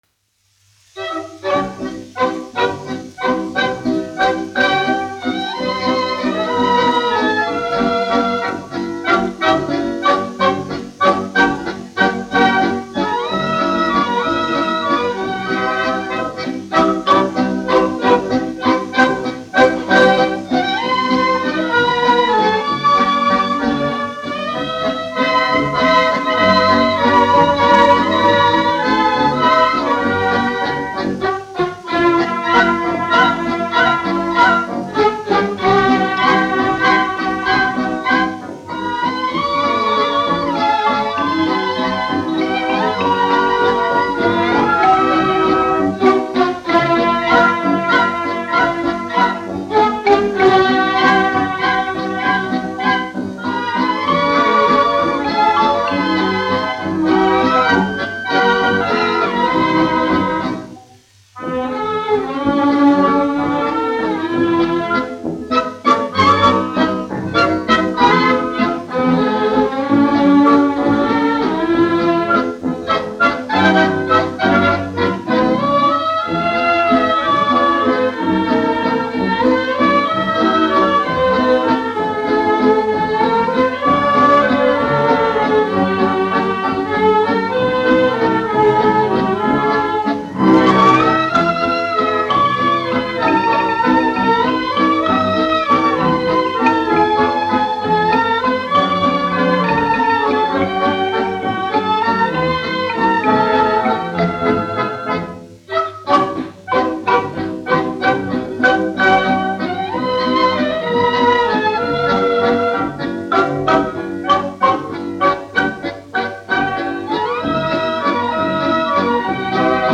1 skpl. : analogs, 78 apgr/min, mono ; 25 cm
Valši
Skaņuplate
Latvijas vēsturiskie šellaka skaņuplašu ieraksti (Kolekcija)